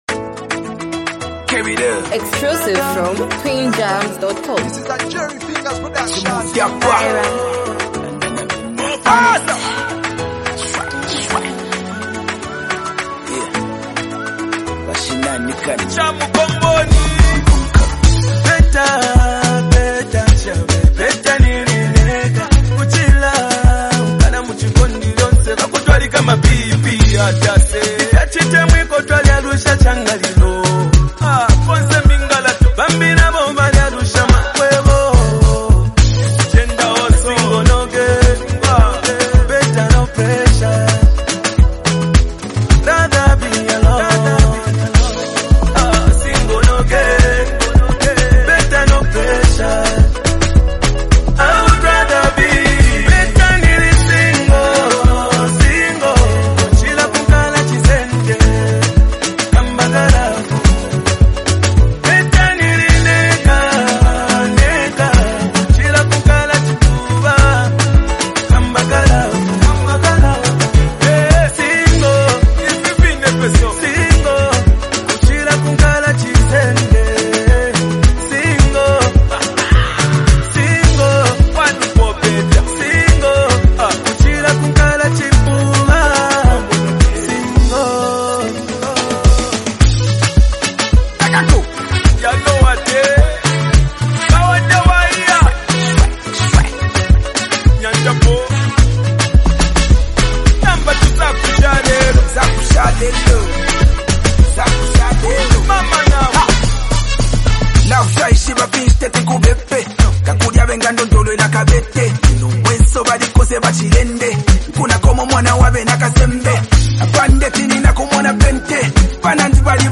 lively and relatable song
His smooth vocals carry a playful yet reflective message
blending melody with punchy rap lines.